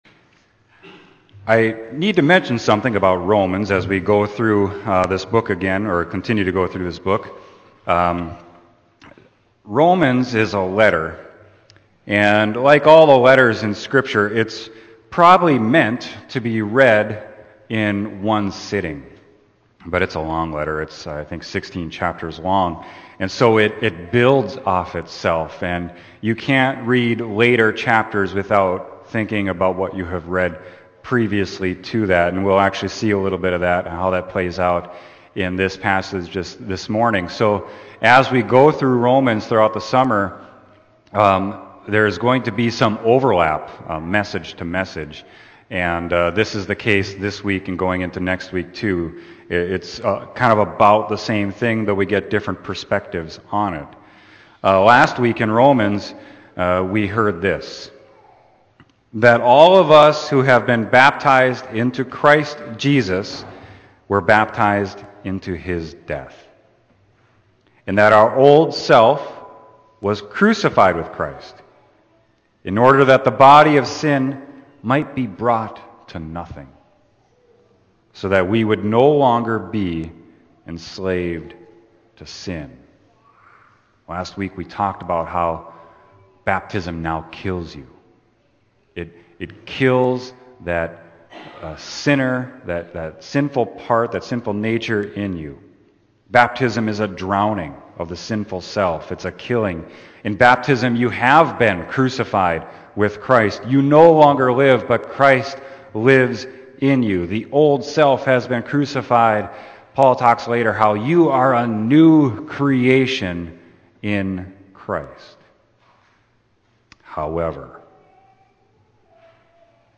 Sermon: Romans 6.12-23